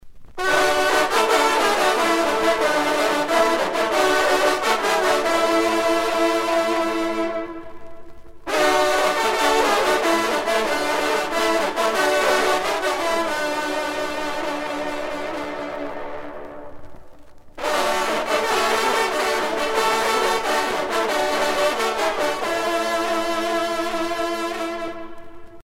trompe - fanfare - circonstances
circonstance : vénerie